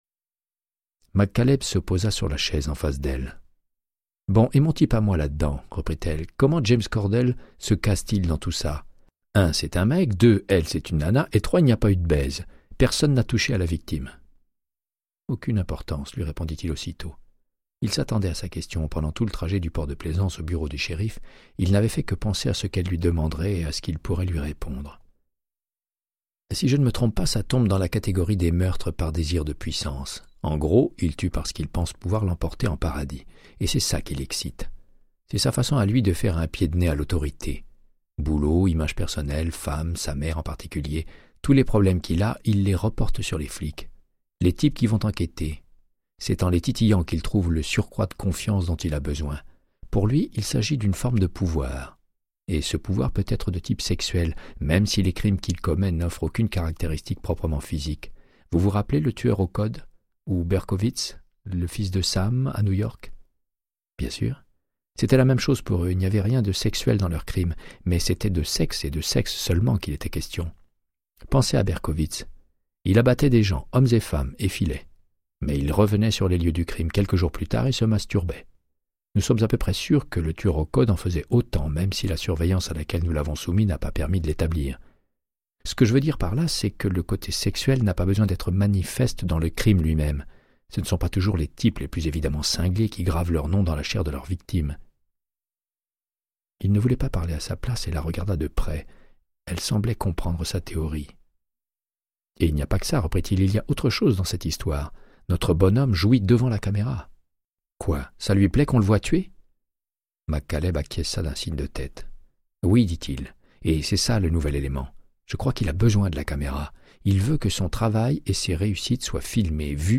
Audiobook = Créance de sang, de Michael Connellly - 79